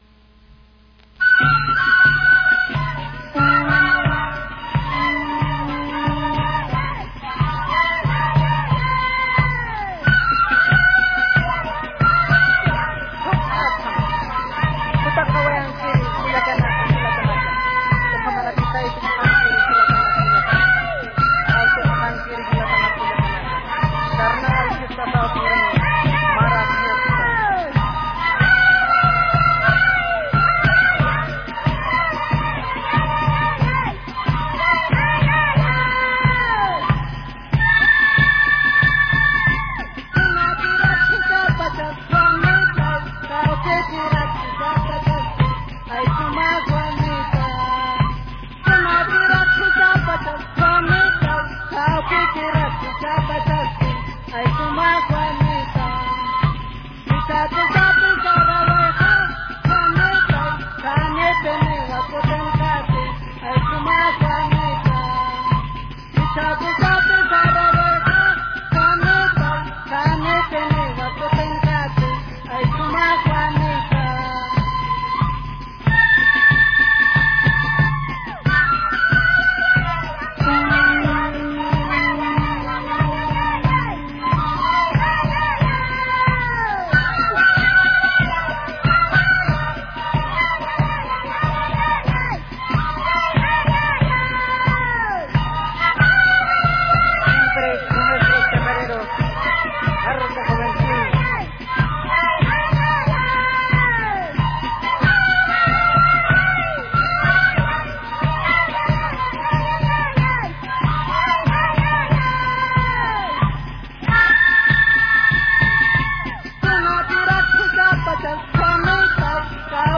Por sus melancólicas notas esta clase de música/danza se supone que tiene un origen fúnebre.
Suma Juanita con copyright popular e interpretado por Juventud Chacareros de Juruhuani, Acora, Puno.